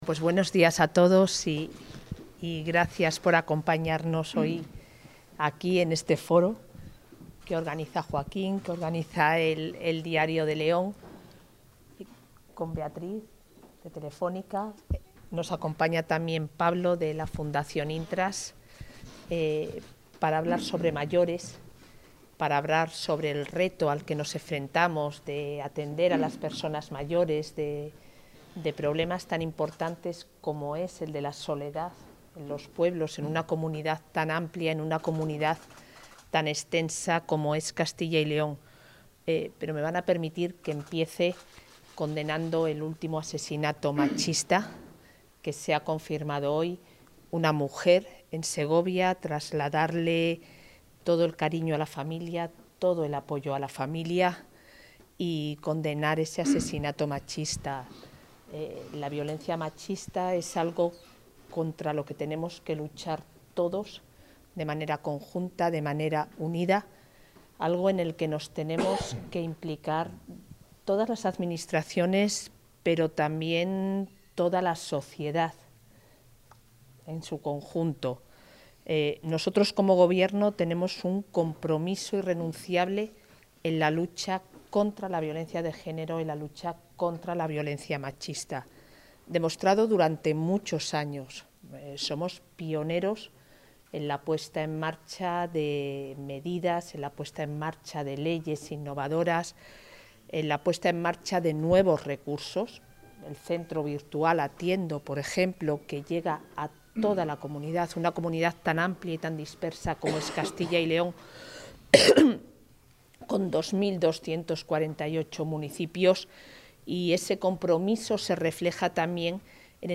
La vicepresidenta de la Junta participa en el foro ‘Smart Village, la solución para la soledad en el medio rural’
Intervención de la vicepresidenta de la Junta.
Intervención de la vicepresidenta de la Junta Mesa redonda sobre soluciones a la soledad en el mundo rural Mesa redonda sobre soluciones a la soledad en el mundo rural Mesa redonda sobre soluciones a la soledad en el mundo rural